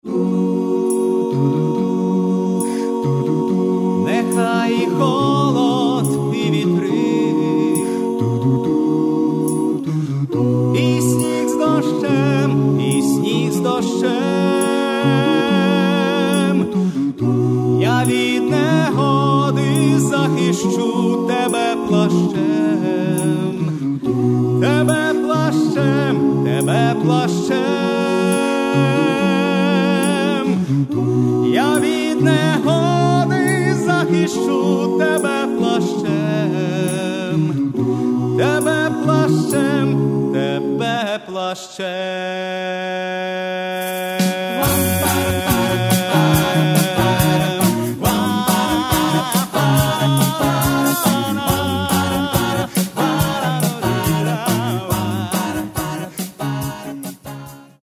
Каталог -> Другое -> Вокальные коллективы